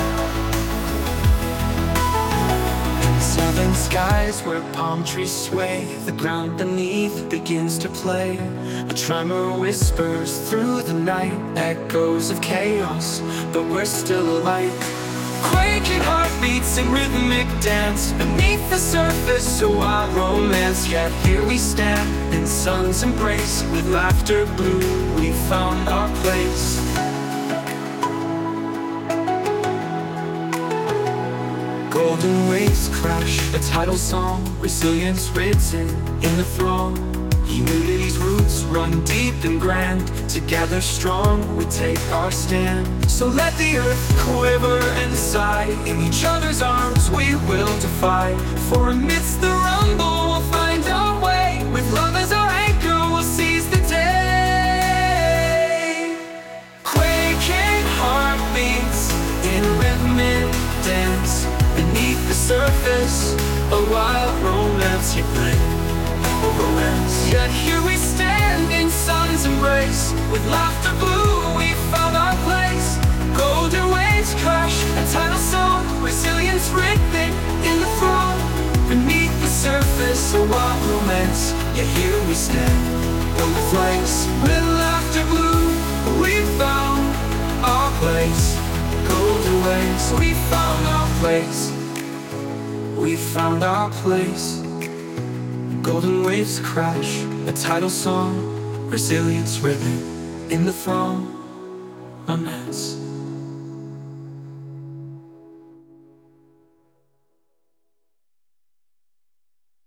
Songs (and quake image) generated by on-line AI
...then asked AI to put it to music...